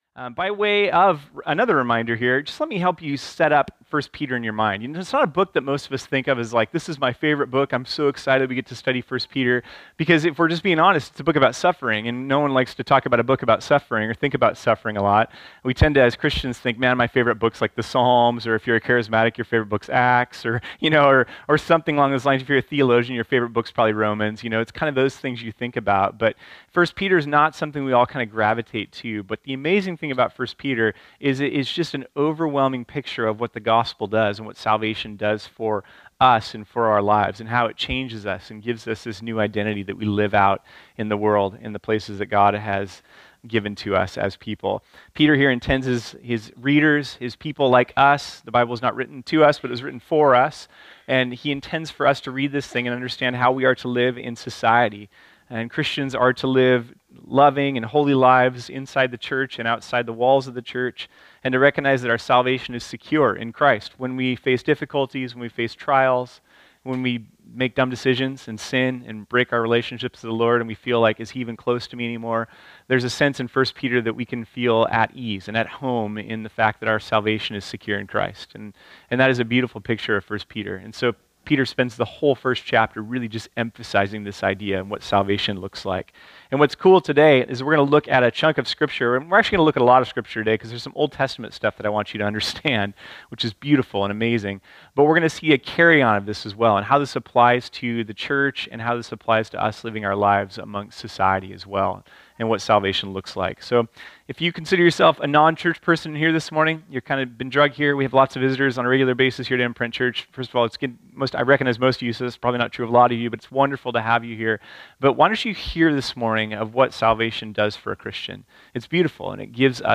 This sermon was originally preached on Sunday, March 4, 2018.